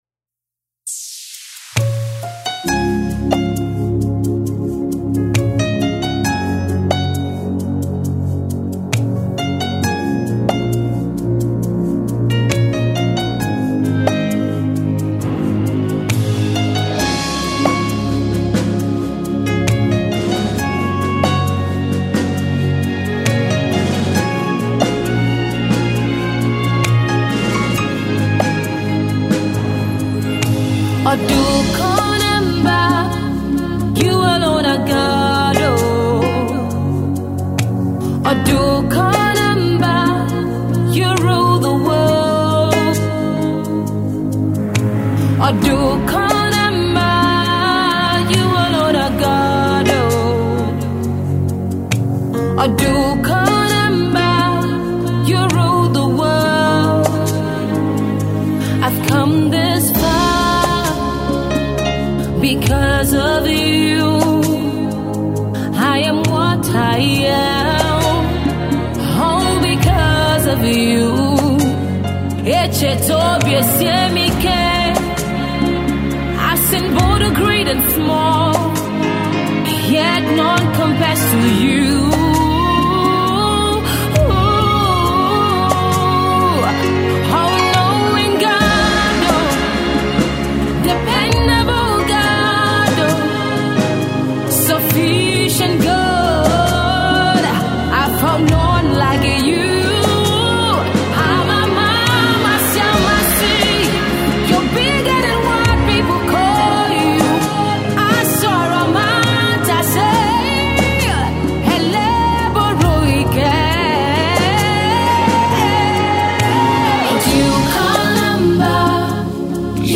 heartfelt worship to God
delivered in her local dialect and English.